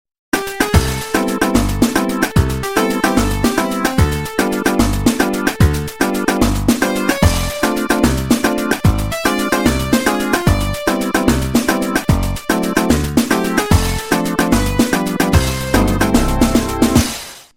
Retro